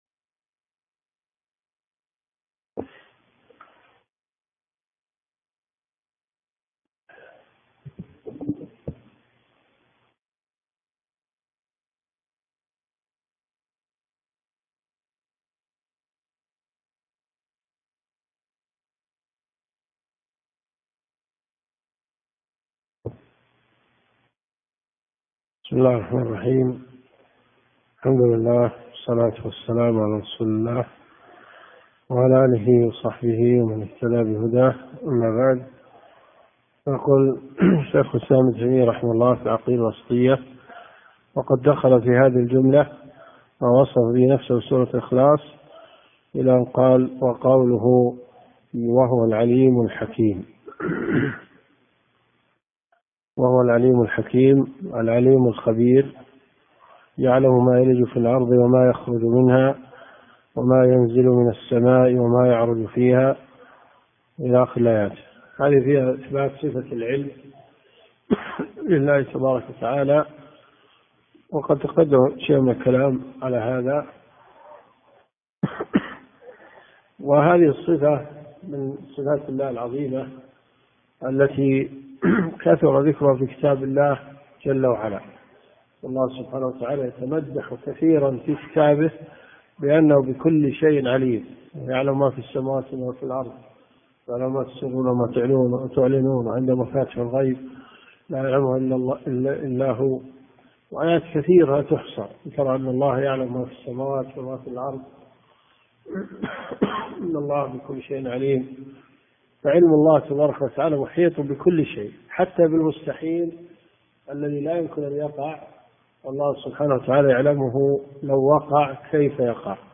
دروس صوتيه